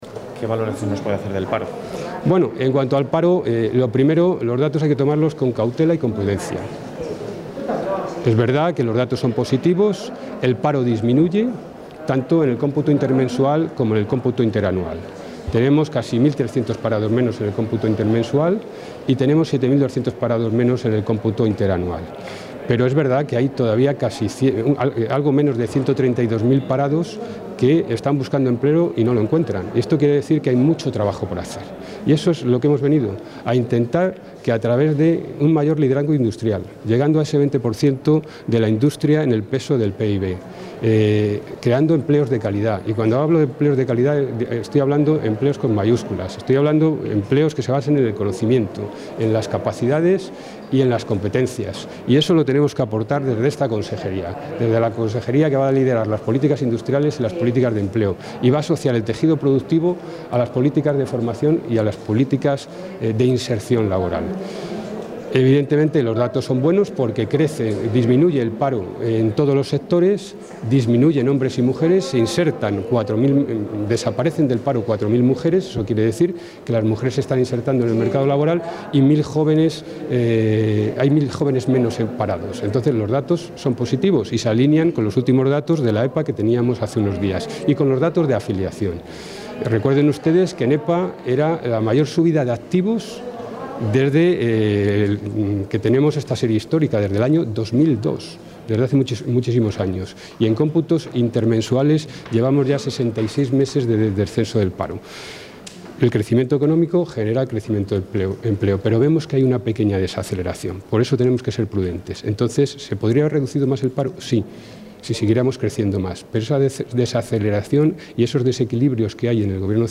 Valoración de los datos del paro de julio Contactar Escuchar 2 de agosto de 2019 Castilla y León | El consejero de Empleo e Industria, Germán Barrio, ha valorado hoy los datos del paro del mes de julio.